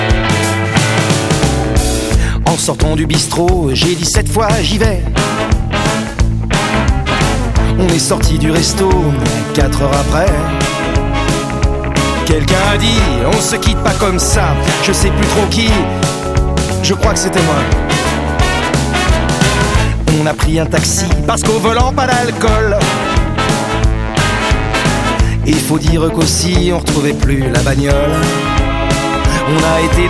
"templateExpression" => "Chanson francophone"